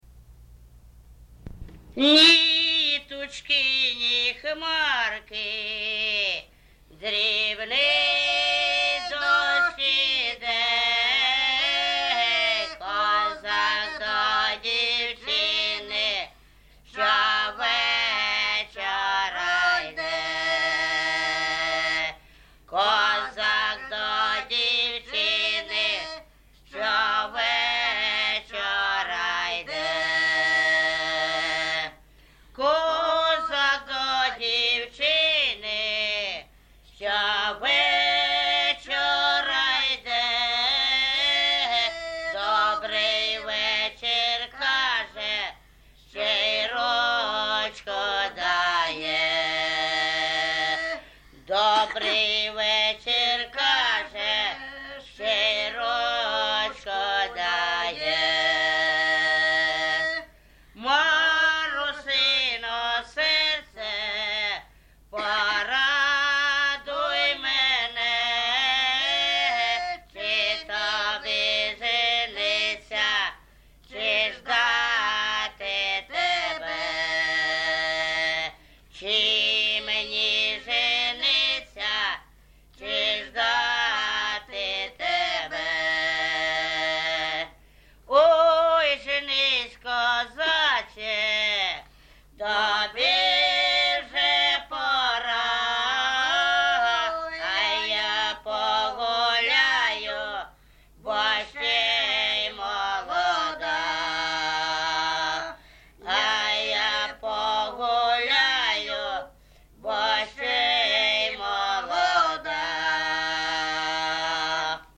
ЖанрПісні з особистого та родинного життя
Місце записус. Гарбузівка, Сумський район, Сумська обл., Україна, Слобожанщина